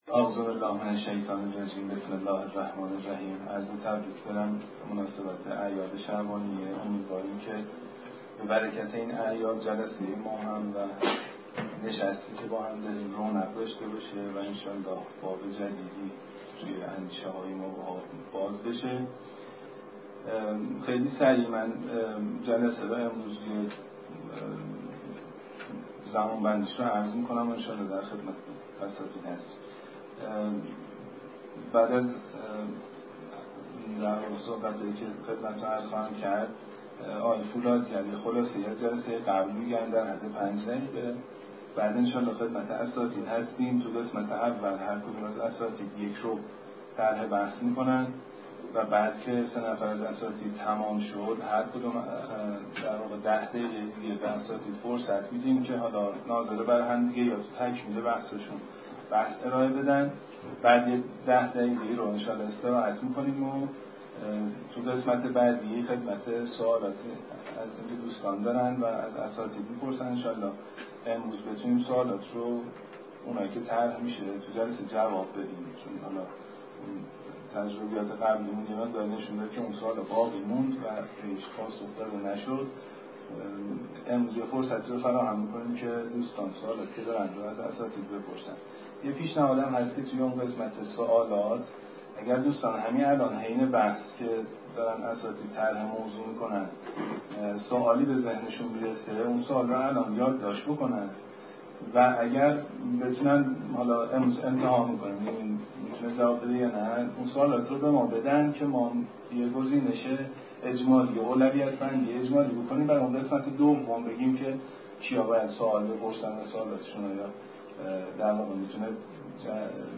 در سالن کنفرانس خانه بیداری اسلامی برگزار شد